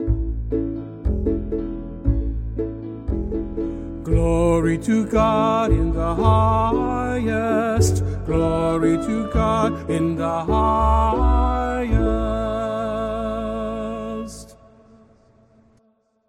Echo version